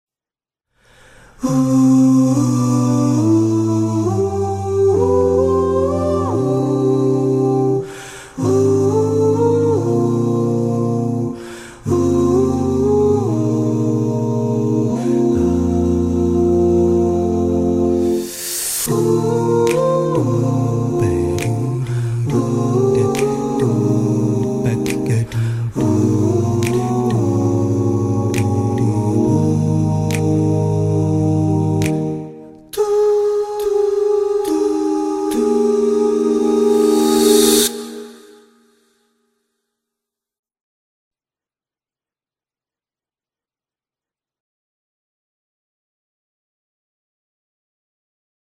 R&Bからジャズまで幅広い音楽的要素を持ち